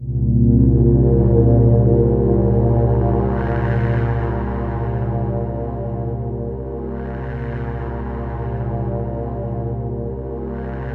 SWEEP06   -R.wav